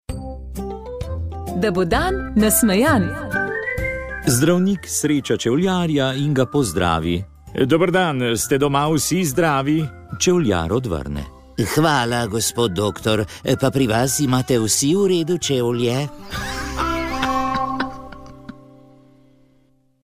Gostili smo začasnega predsednika državnega zbora Mateja Tonina. Z njim smo se pogovarjali o aktualnih političnih zadevah, o novonastajajoči vladi, sestavljanju novih delovnih teles v državnem zboru ter tudi o tem, kako ocenjuje svoje delo vodenja državnega zbora zadnjih dveh mesecev.